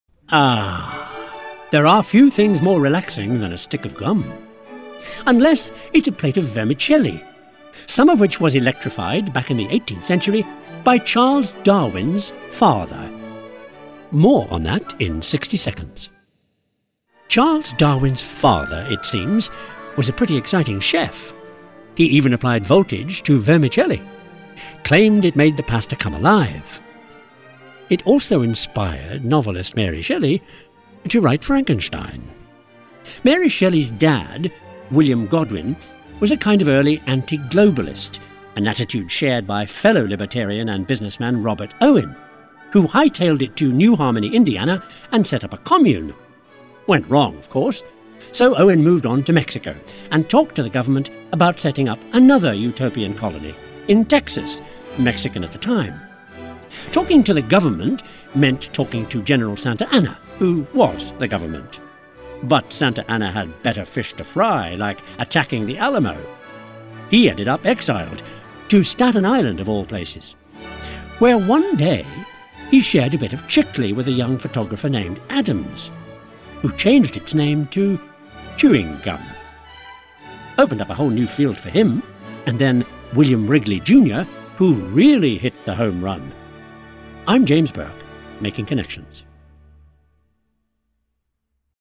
James Burke's Connections: Gum (Baroque music bed)::KeyMarket Media